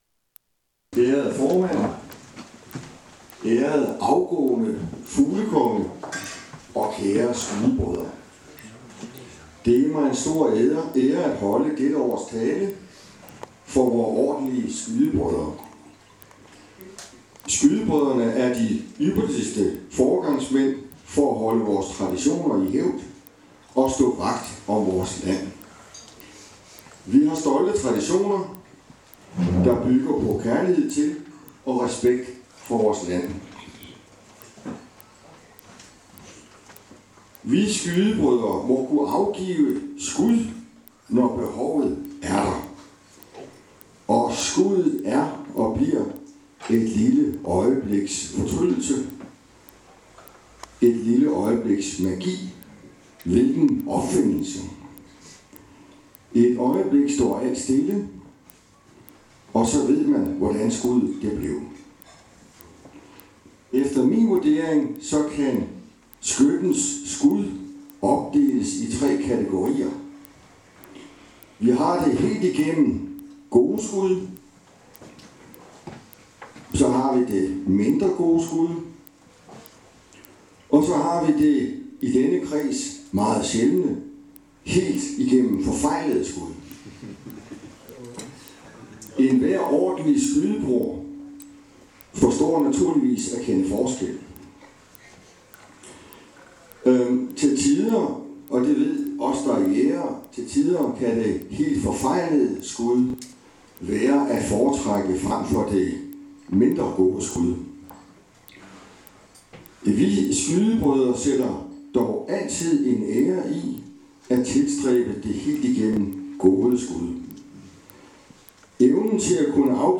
tale for skytterne